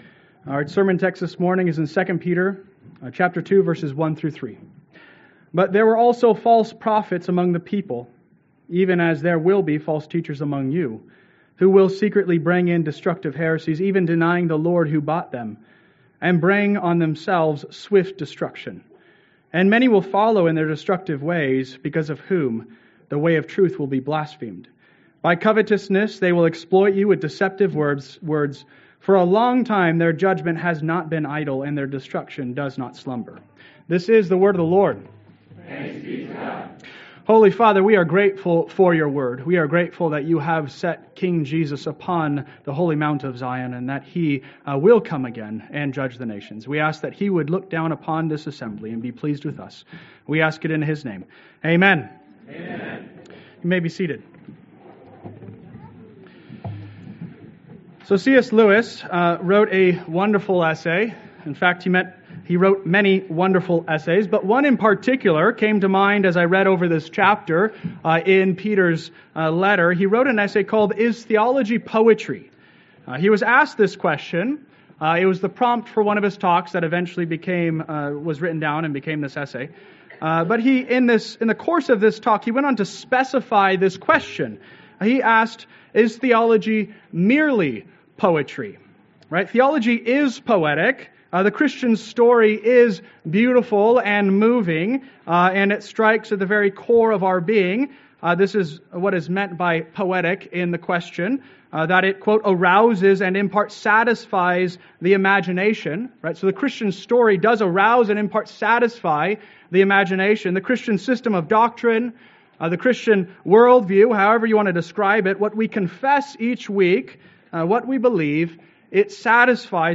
Sermon Outline: 11-03-24 Outline 2 Peter 2a (Jesus Transfigured & Other jesuses)